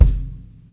kick 9.wav